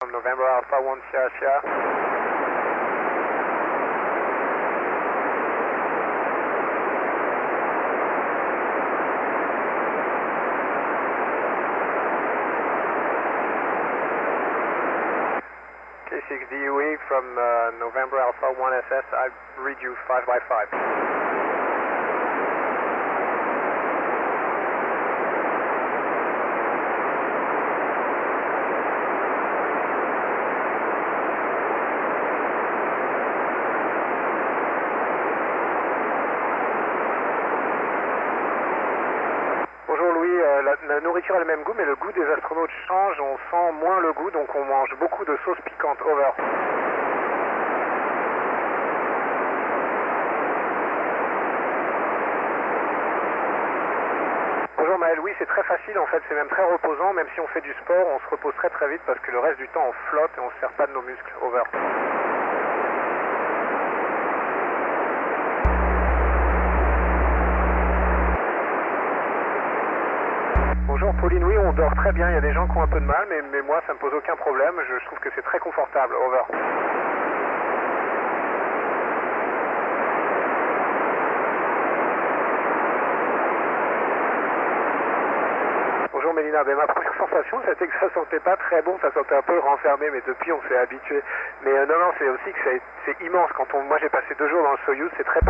I am getting some feed back from my antenna rotator controller ...I'll have to fix that...